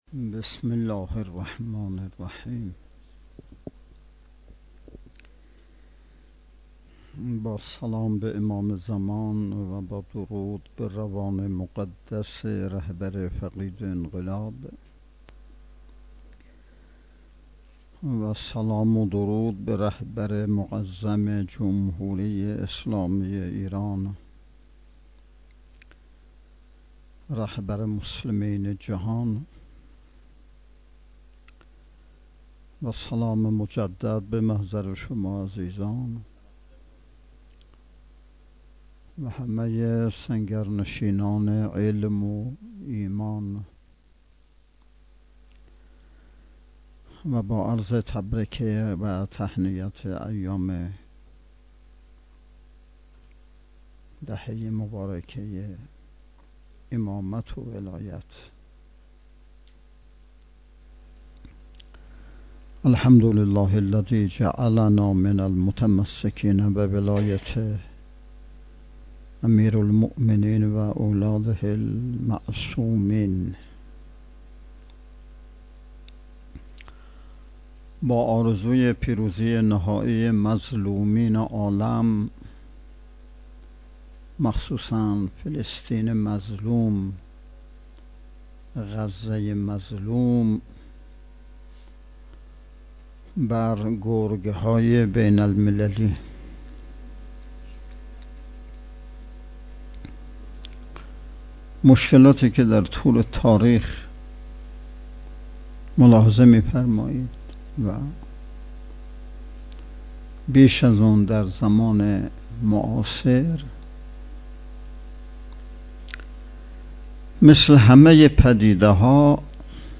پنجمین نشست ارکان شبکه تربیتی صالحین بسیج با موضوع تربیت جوان مؤمن انقلابی پای کار، صبح امروز (۳۱ خرداد) با حضور و سخنرانی نماینده ولی فقیه در استان، برگزار شد.